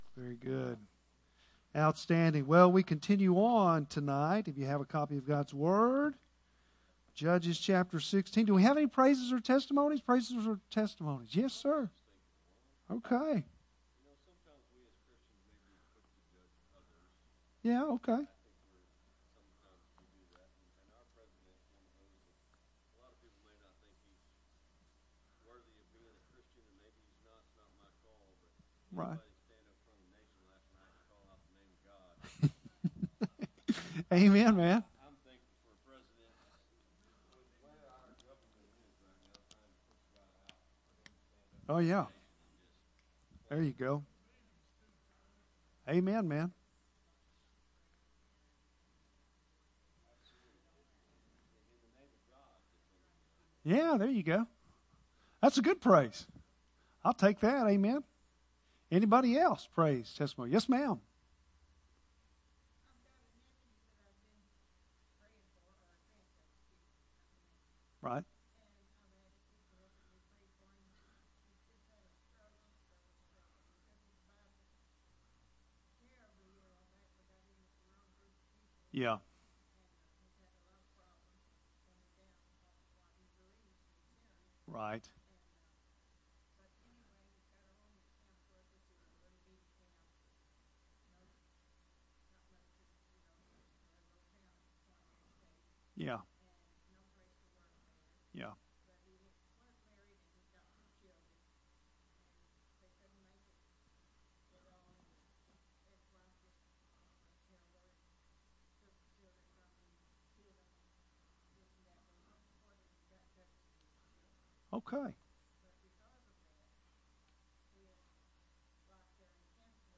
Bible Text: Judges 16:21-31 | Preacher